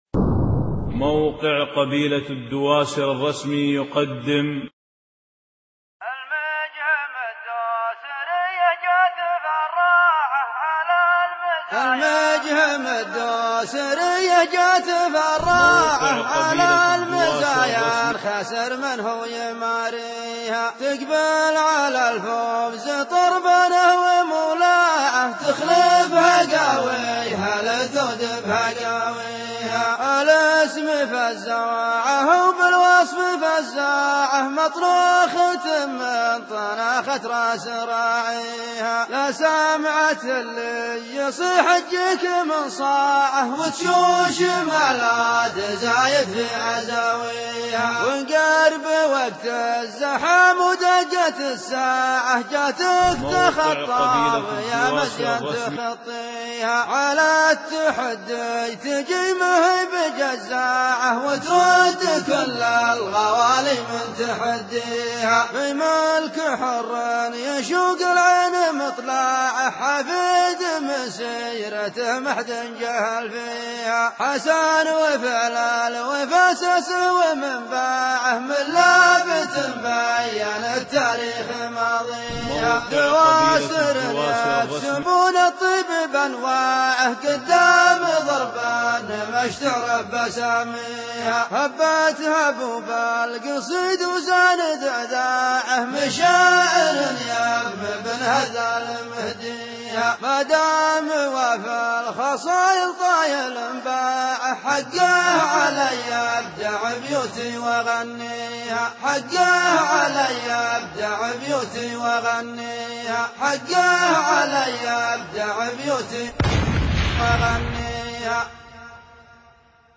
قصائد صوتيّة حصريّة للموقع